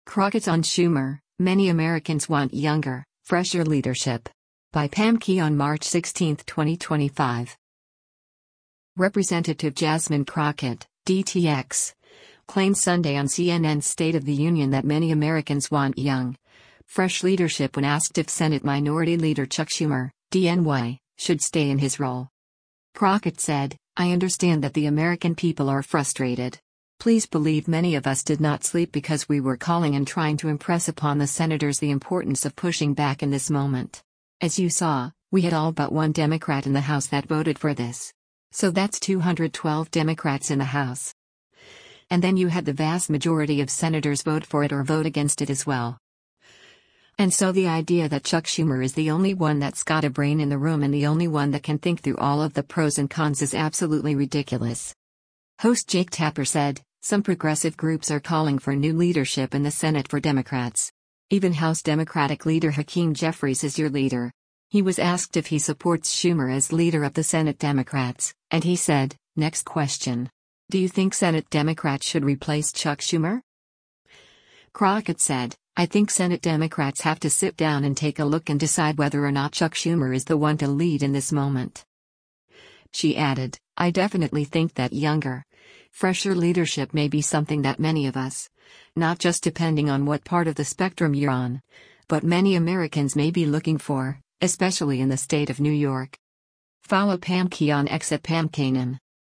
Representative Jasmine Crockett (D-TX) claimed Sunday on CNN’s “State of the Union” that many Americans want young, fresh leadership when asked if Senate Minority Leader Chuck Schumer (D-NY) should stay in his role.